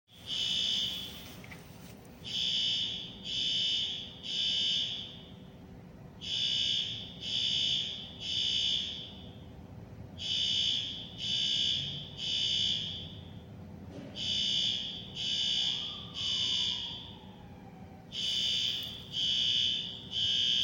Alertamiento contra incendios o emergencias
Alertamiento-contra-incendios-o-emergencia.mp3